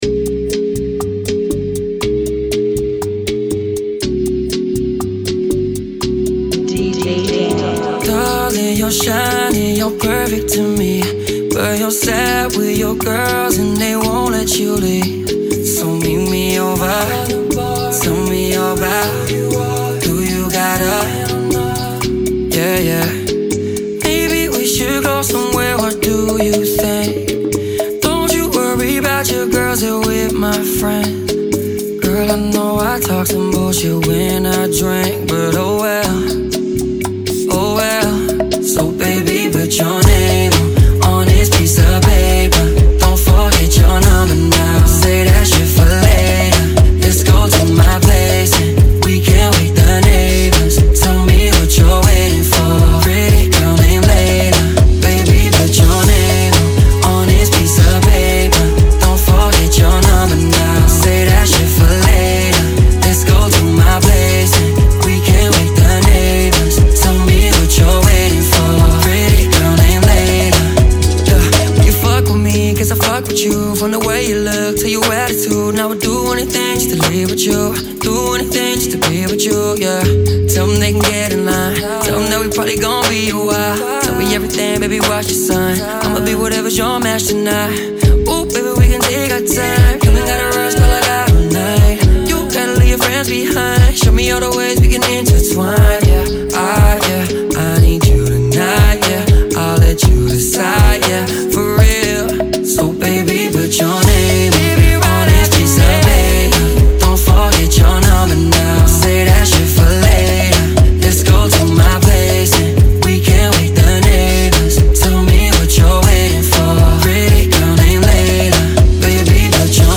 120 BPM
Genre: Bachata Remix